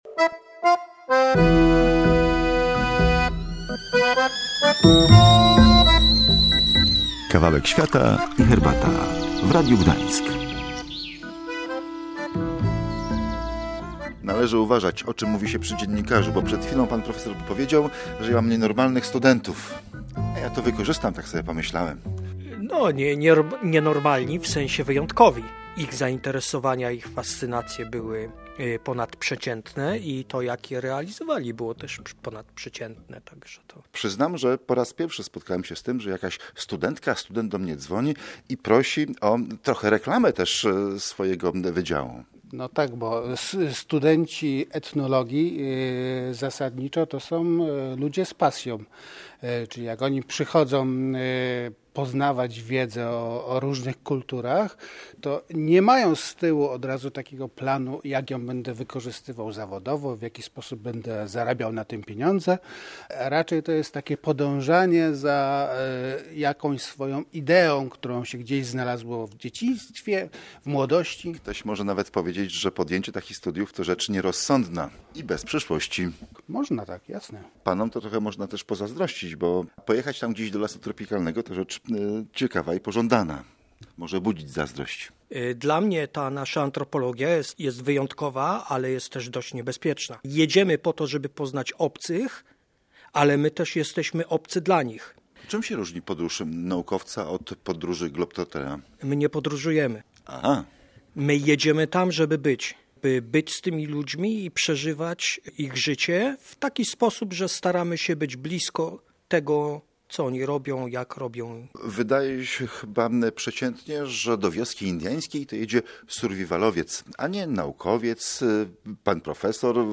Zapraszamy do posłuchania pierwszego z kilku wywiadów z antropologami. 10 listopada rozmawiali o Amazonii pracownicy Zakładu Etnologii i Antropologii Kulturowej Uniwersytetu Gdańskiego: dr hab.